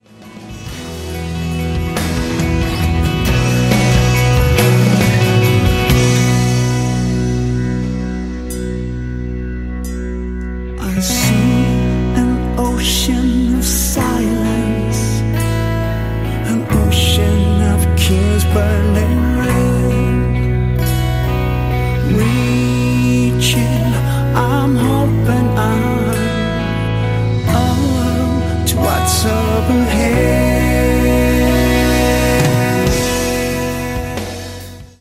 big rock & roll show
Genre: Rock.